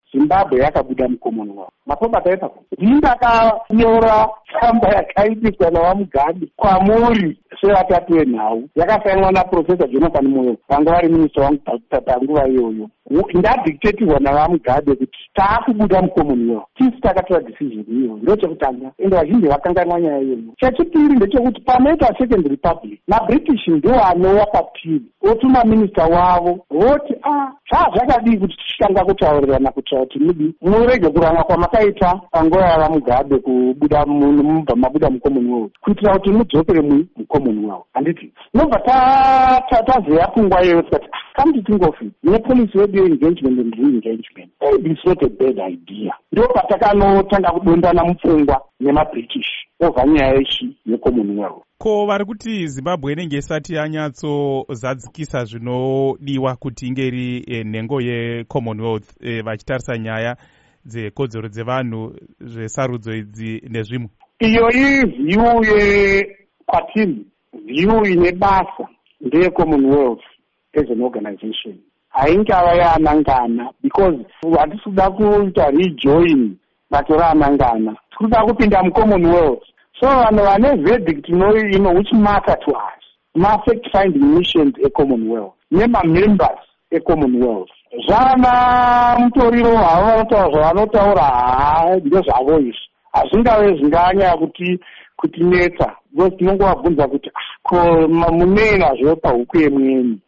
Hurukuro naVaGeorge Charamba